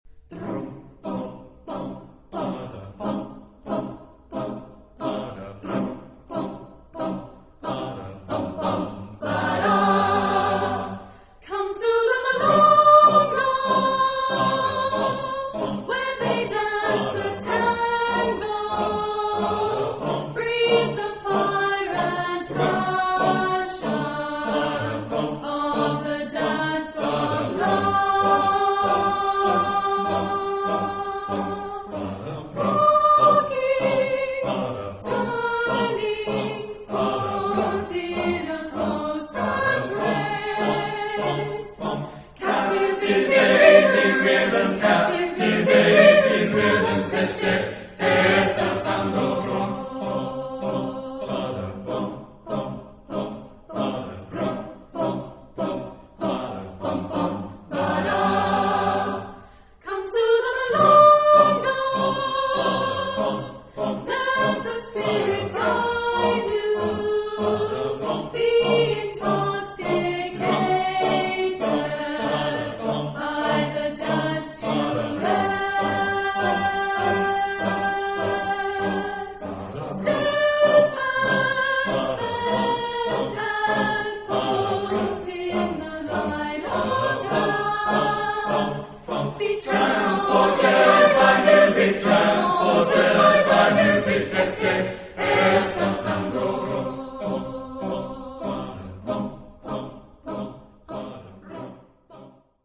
Texte en : anglais ; onomatopées
Genre-Style-Forme : Profane ; Danse
Caractère de la pièce : rythmé ; balancé
Type de choeur : SATB  (4 voix mixtes )
Tonalité : do mineur
Consultable sous : 20ème Profane Acappella